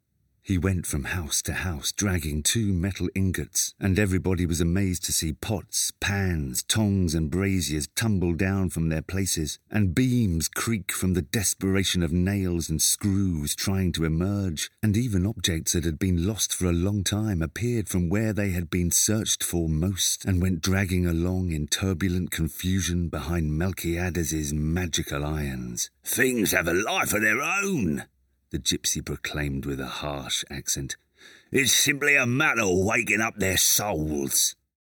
Giọng nam người Anh
Sách nói/truyện